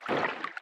Sfx_creature_brinewing_swim_fast_03.ogg